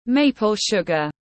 Đường phong tiếng anh gọi là maple sugar, phiên âm tiếng anh đọc là /ˌmeɪ.pəl ˈʃʊɡ.ər/
Maple sugar /ˌmeɪ.pəl ˈʃʊɡ.ər/